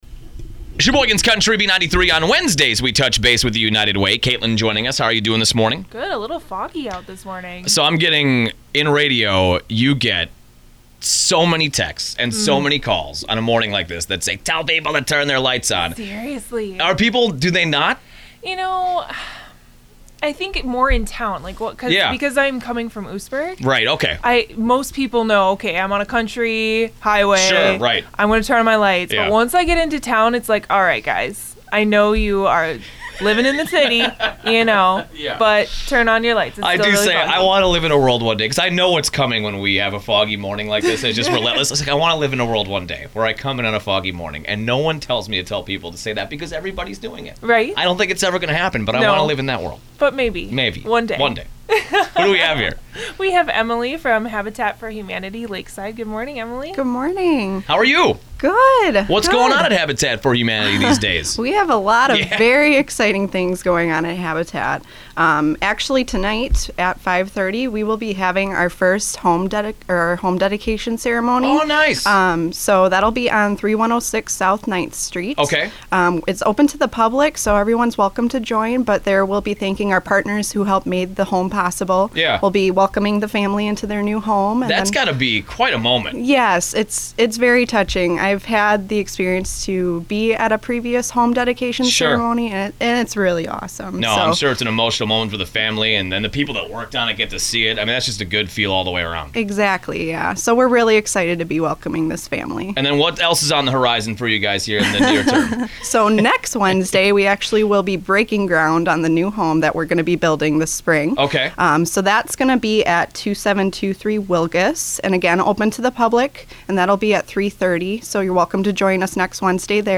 Habitat for Humanity Lakeside - Radio Spot